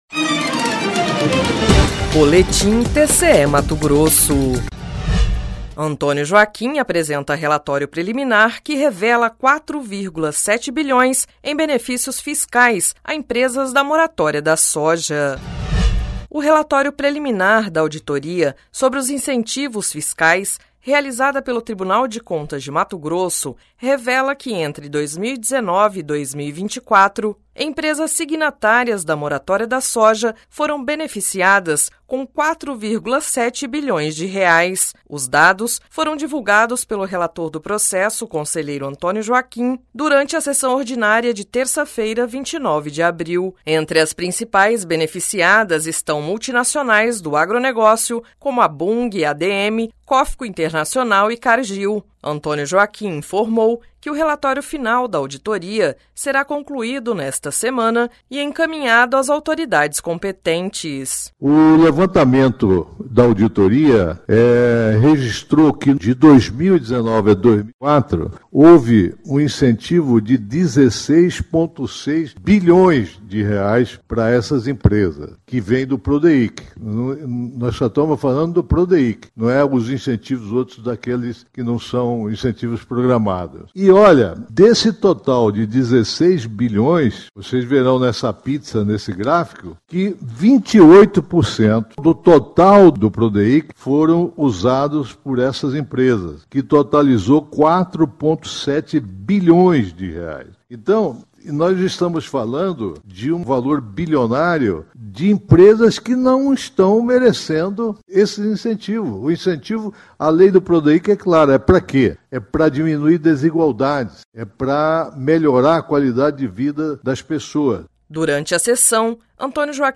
Sonora: Antonio Joaquim – conselheiro do TCE-MT
Sonora: Sérgio Ricardo – conselheiro-presidente do TCE-MT